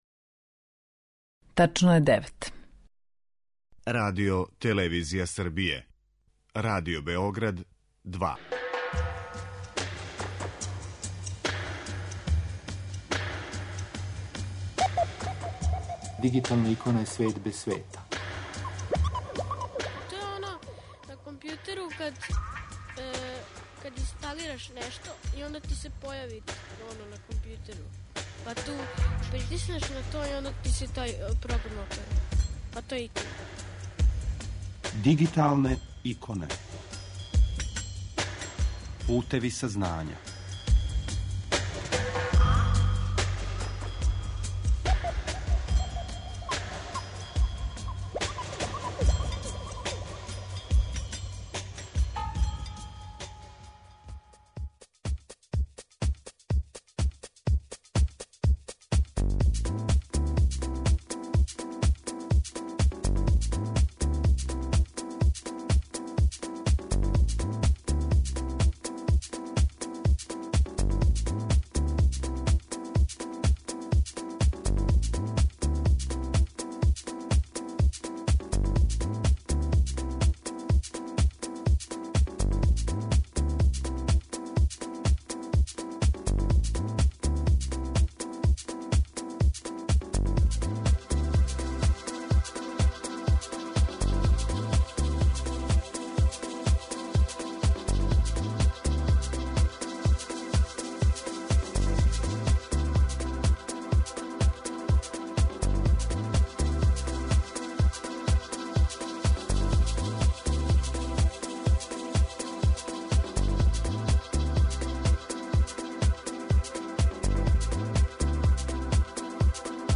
У данашњој емисији са нама је психолог